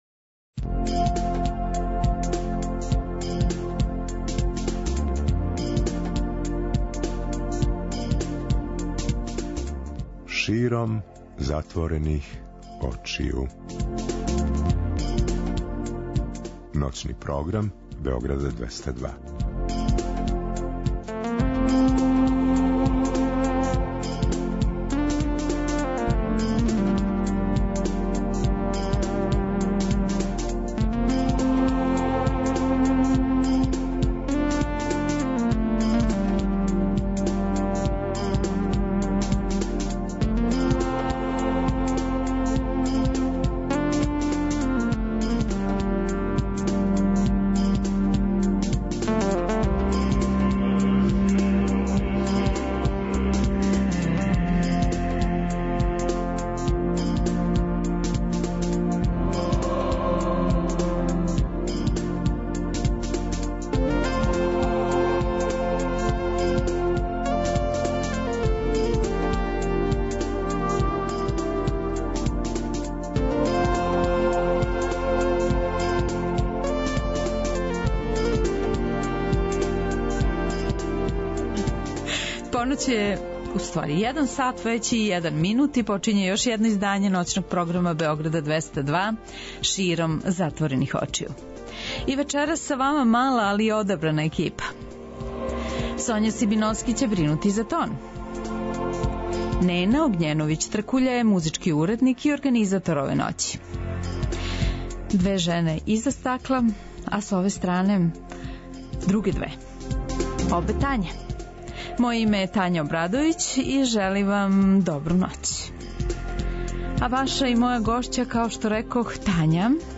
Гост Ноћног програма “Широм затворених очију“ (01.00) је глумица Тања Пујин.